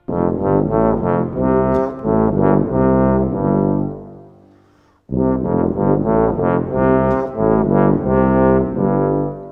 TUBA.wav